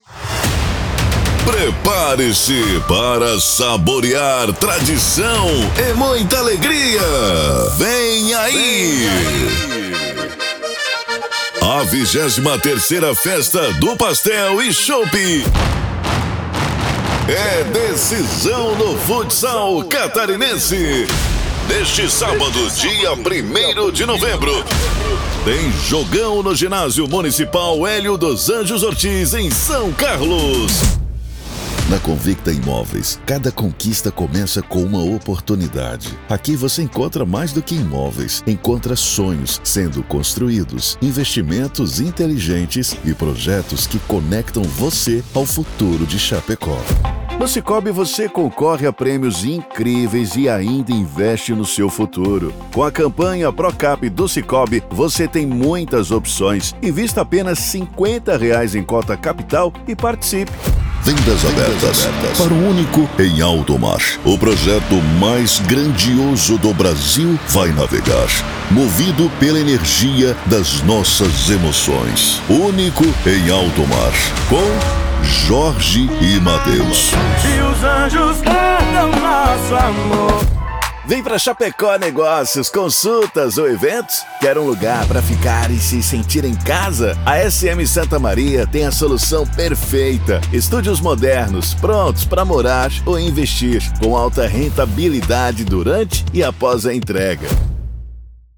Spot Comercial
Vinhetas
Impacto
Animada